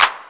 Cardiak Clap 2.wav